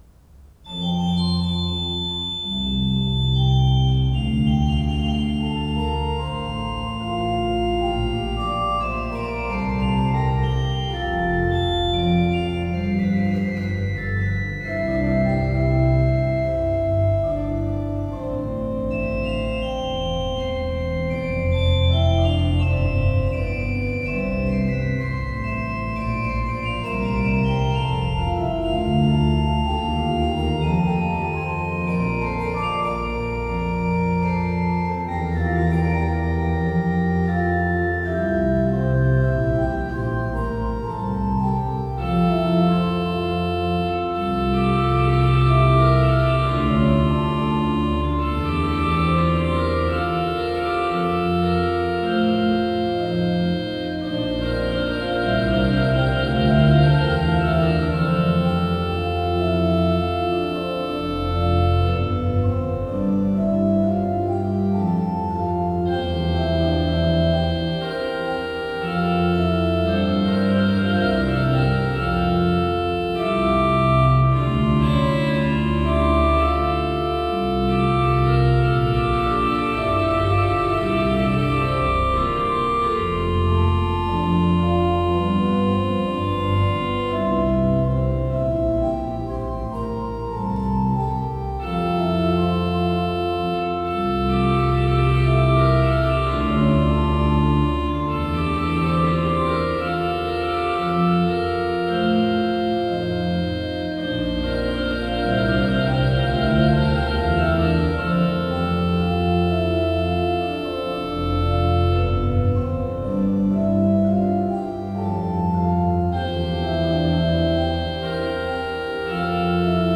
Audio/Video - Organo Basilica Cattedrale di Fossano
Registrizioni amatoriali realizzate con Zoom H5N con capsula Zoom Msh-6 e/o microfoni esterni stereo AKG SE300B
Brani periodo Barocco